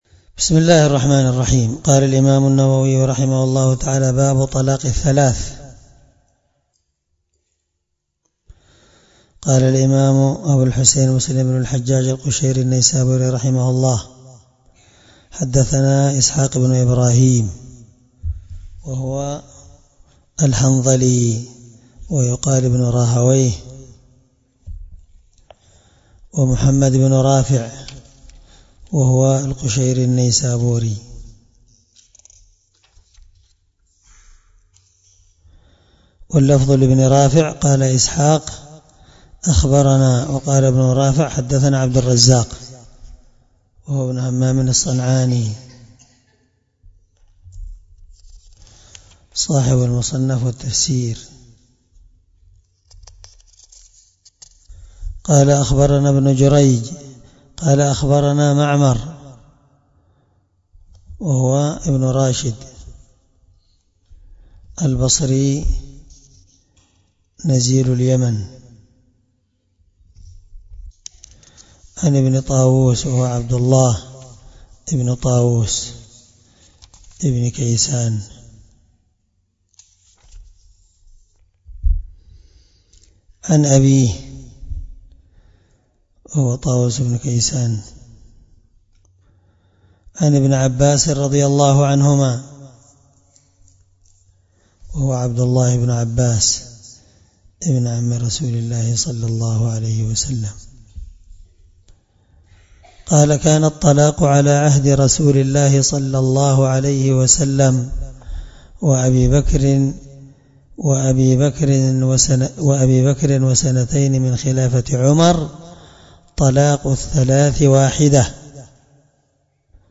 الدرس3من شرح كتاب الطلاق حديث رقم(1472) من صحيح مسلم